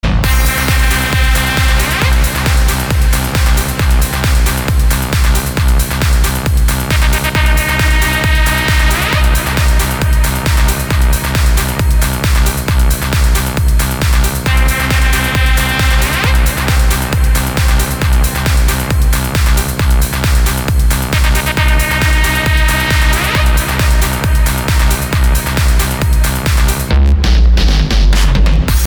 громкие
dance
Electronic
электронная музыка
без слов
club
Trance
Bass